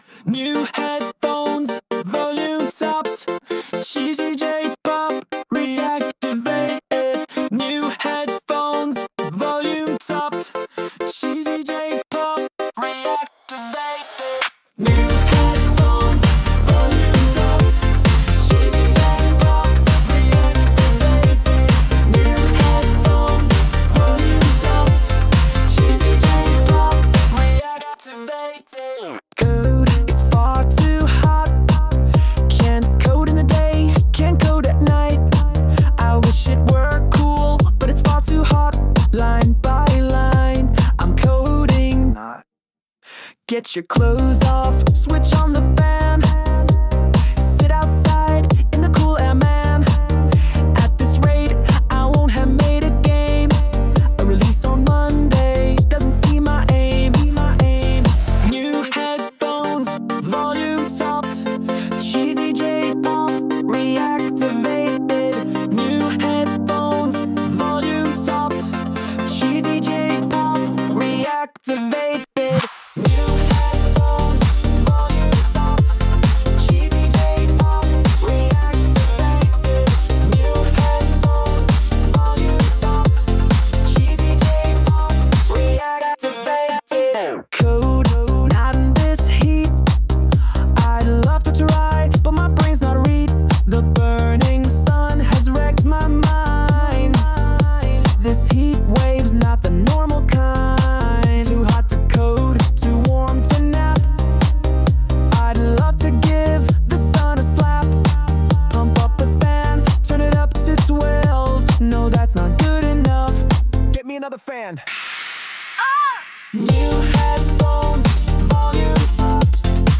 They've been on charge, and will be doing the whole "Headphones On, Volume Up, Cheesy J-Pop, Activated" thing, later on.
Sung by Suno